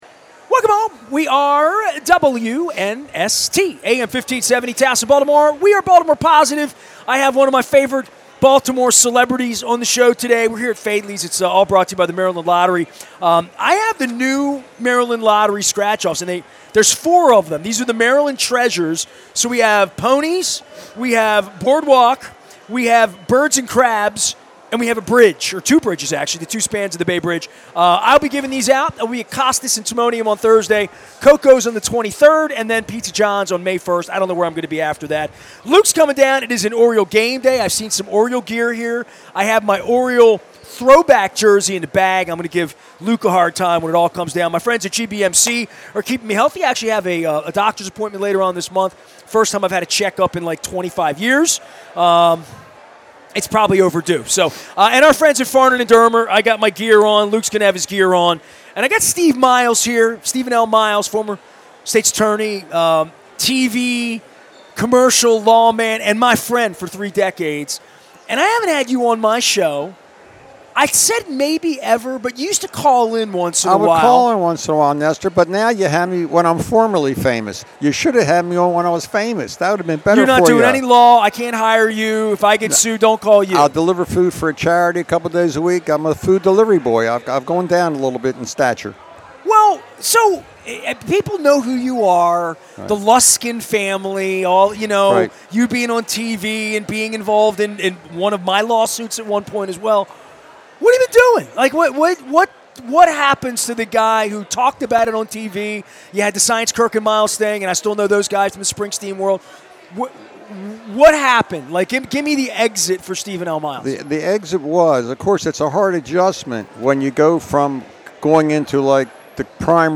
at Faidley's Seafood on the Maryland Crab Cake Tour